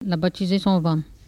Patois - archives